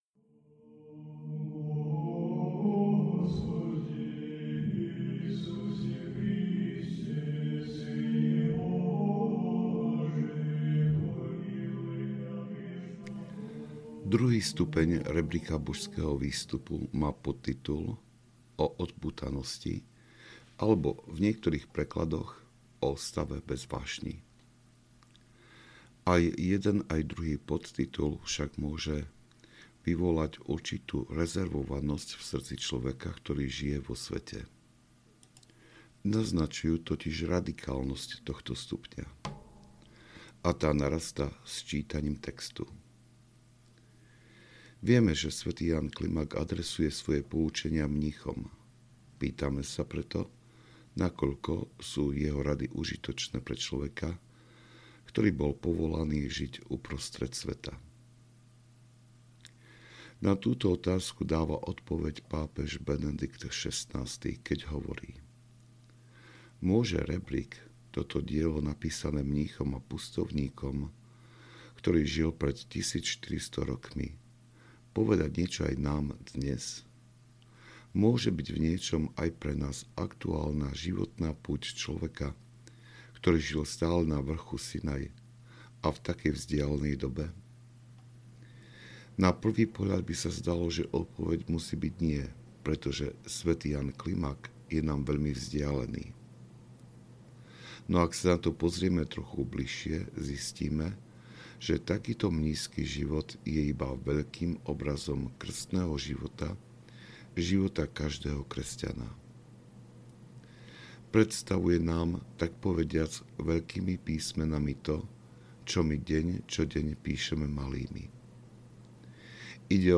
O odpútanosti audiokniha
Ukázka z knihy